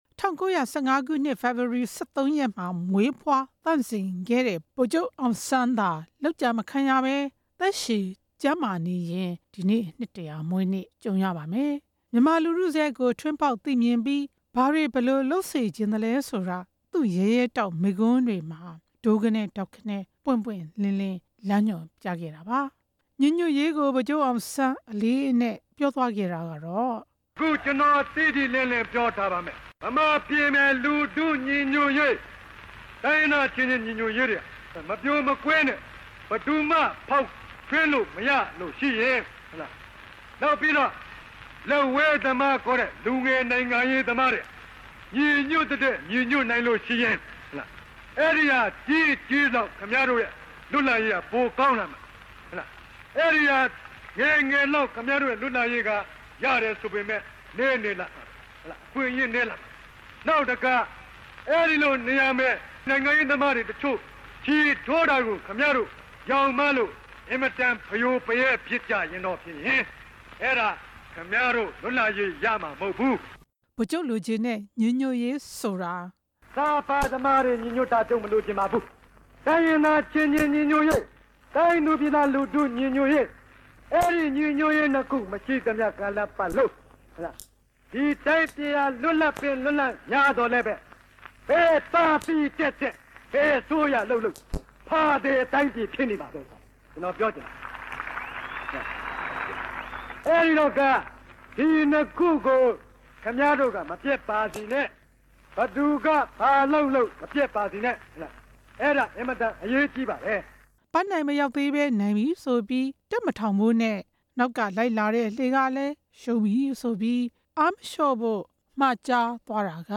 ဗိုလ်ချုပ်ရဲ့ မိန့်ခွန်းတချို့